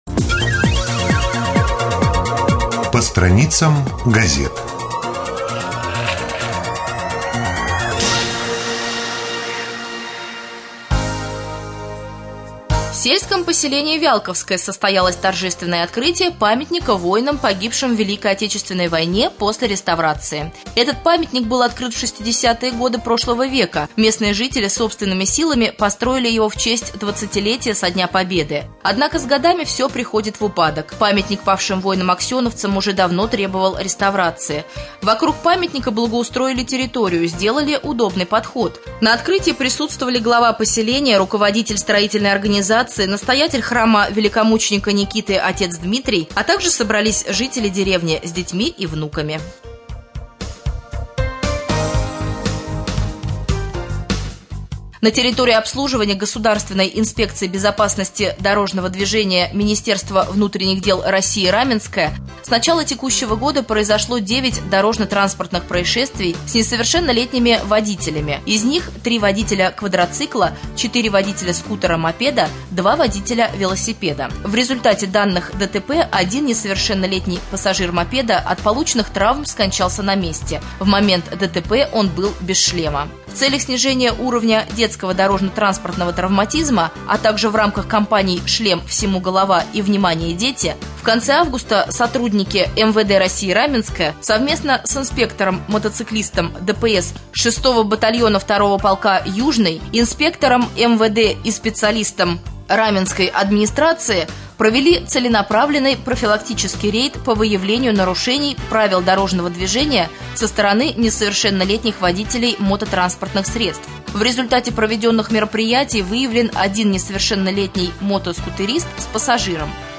09.09.2014г. в эфире Раменского радио - РамМедиа - Раменский муниципальный округ - Раменское
1.Рубрика «По страницам прессы».